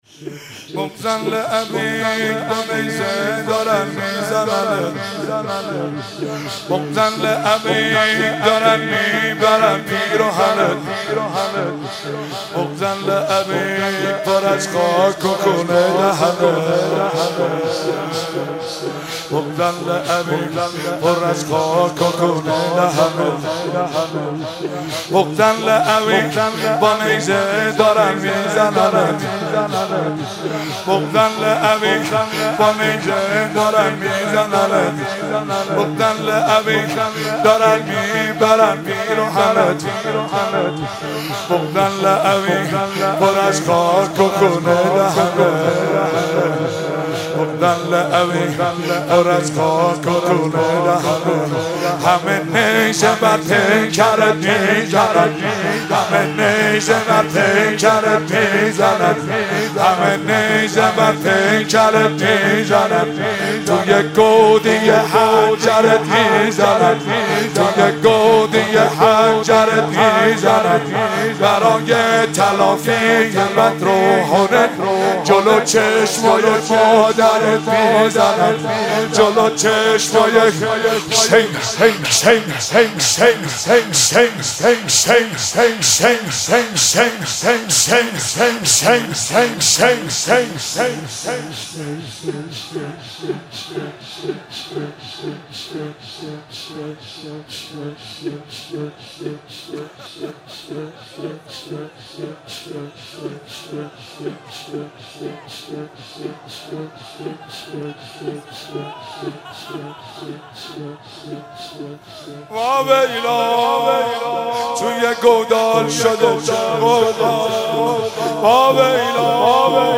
شور شب دهم محرم
محرم۱۳۹۷هیئت فاطمیون قم